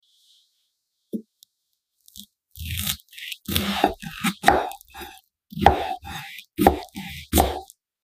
Cutting into the perfect kiwi sound effects free download